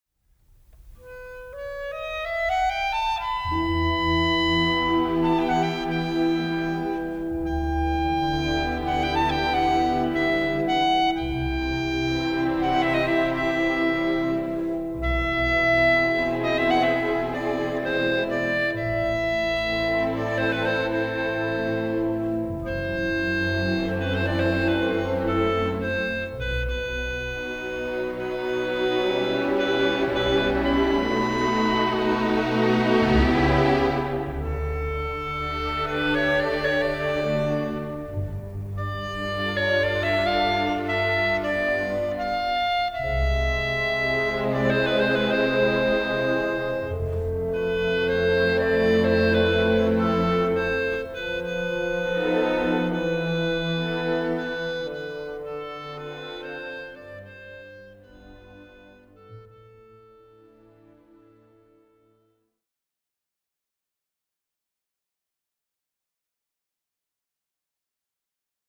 Holzblasinstrumente
Tonbeispiel Klarinette klassisch:
19-Klarinette-klassisch.mp3